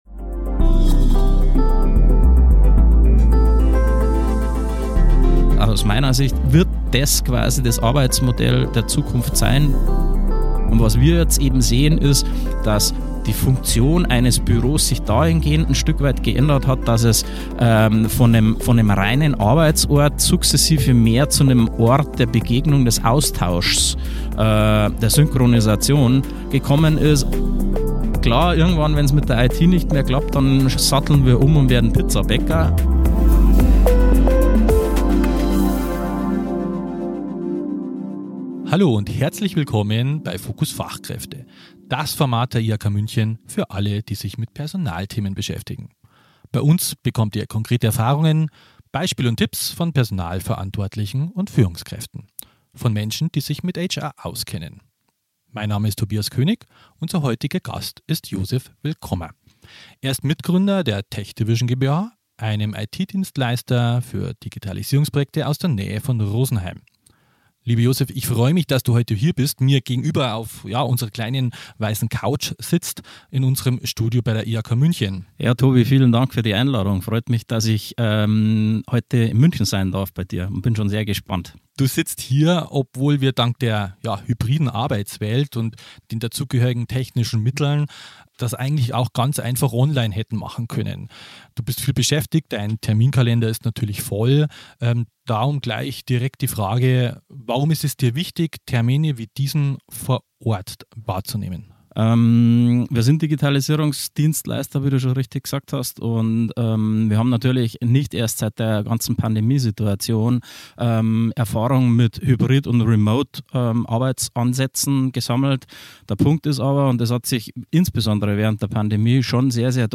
TechDivision setzt dabei auf ein positives Anreizsystem statt auf Zwang. In dieser Folge sprechen wir ungeschminkt, echt bayerisch und direkt auf den Punkt über die Hürden und Chancen auf dem Weg zurück ins Büro.